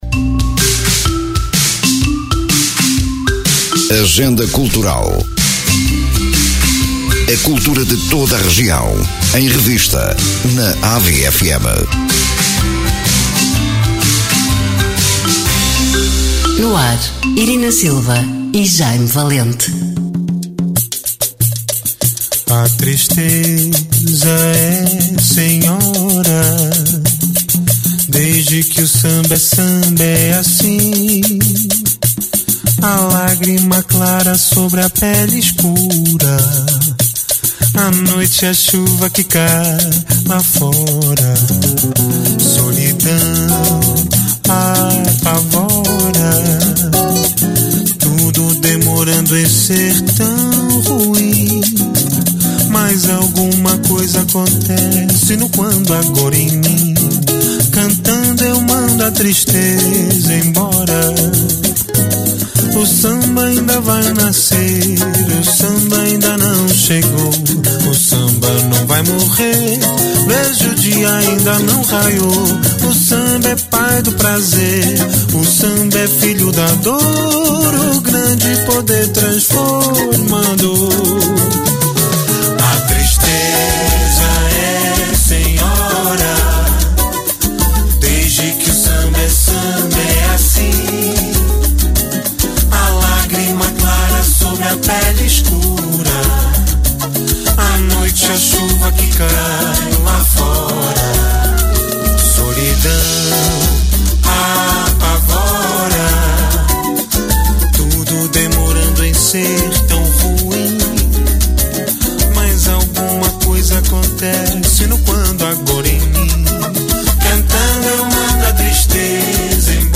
Programa com conteúdos preparados para ilustrar os eventos a divulgar, com bandas sonoras devidamente enquadradas.